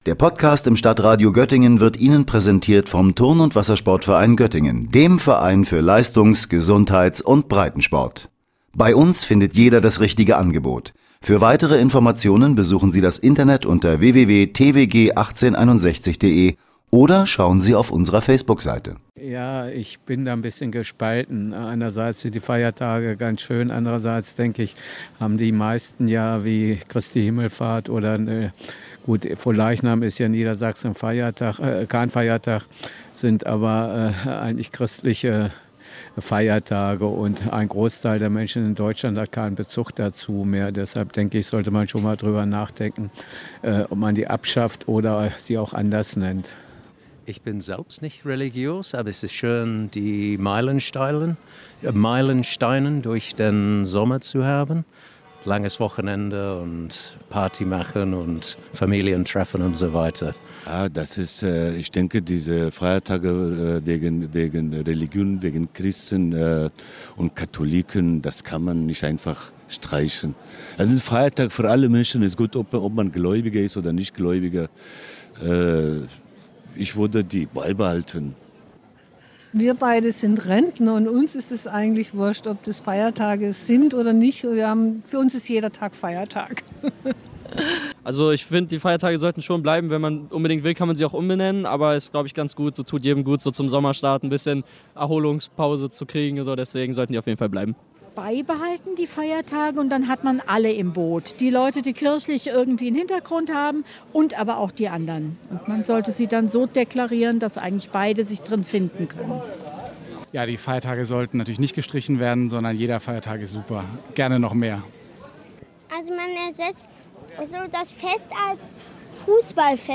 Die Mehrheit der bundesweiten gesetzlichen Feiertage ist dagegen weiterhin christlich geprägt. Ist das noch zeitgemäß oder sollten diese Feiertage wie jetzt Christi Himmelfahrt ersetzt, vielleicht sogar abgeschafft werden? Wir haben uns umgehört.